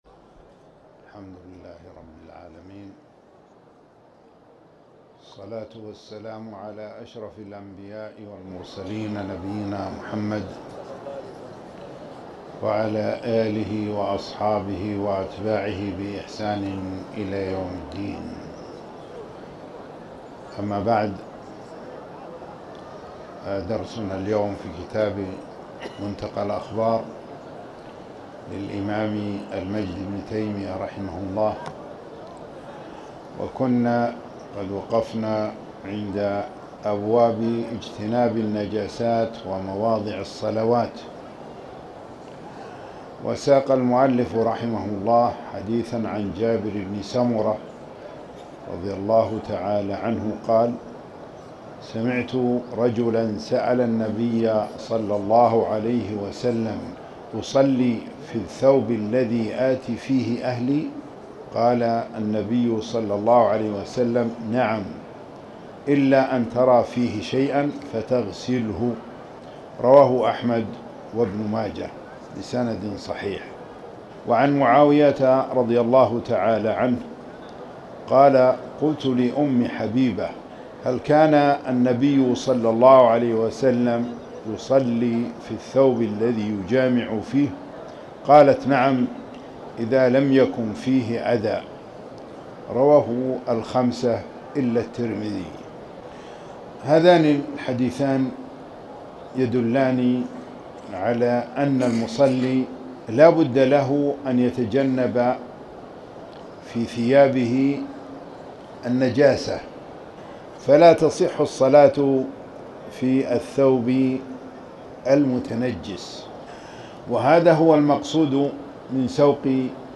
تاريخ النشر ٣٠ جمادى الأولى ١٤٤٠ هـ المكان: المسجد الحرام الشيخ